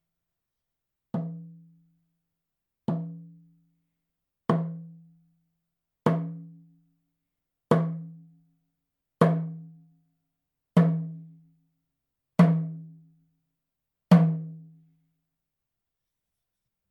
ネイティブ アメリカン（インディアン）ドラム NATIVE AMERICAN (INDIAN) DRUM 10インチ（elk アメリカアカシカ・ワピチ）
ネイティブアメリカン インディアン ドラムの音を聴く
乾いた張り気味の音です